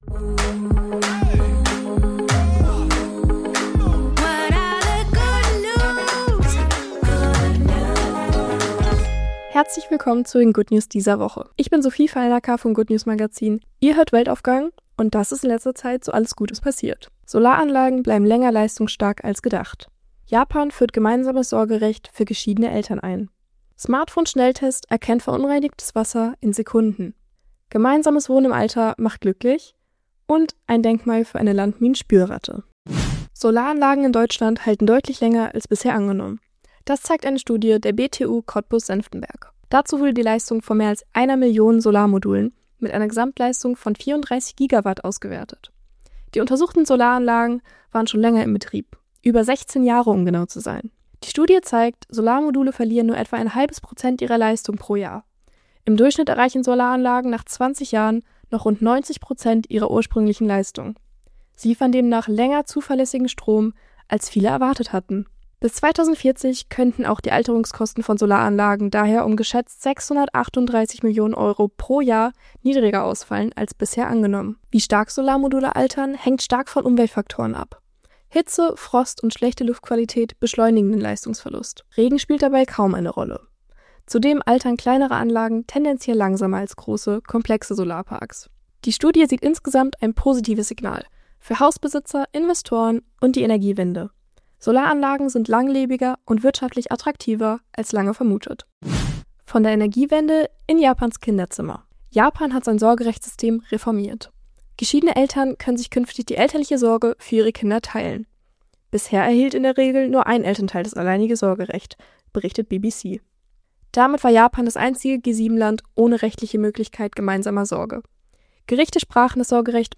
Die Good News der Woche - präsentiert vom Good News Magazin
Jede Woche wählen wir aktuelle gute Nachrichten aus und tragen sie